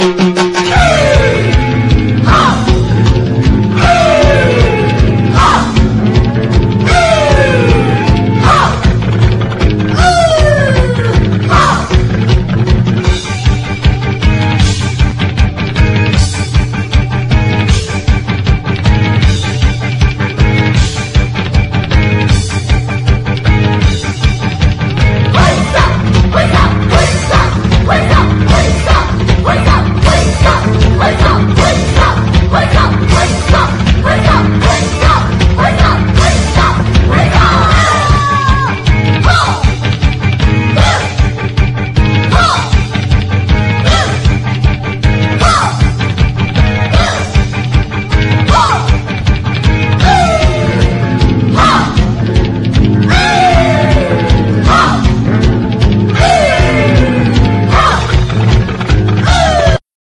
JAPANESE / 80'S / GS (JPN)
溌剌としたユニゾン・ヴォーカルで弾けまくった
ファズったギターに哀愁歌謡メロがモロGSな